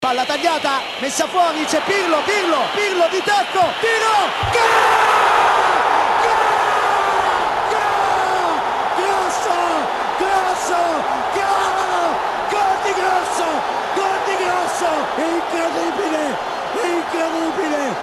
Explicação Fabio Caressa’s commentary made history in Italian sports journalism. The Sky Italia commentator allowed emotions to get the better of him when Fabio Grosso scored the decisive goal in the final seconds of the match against Germany.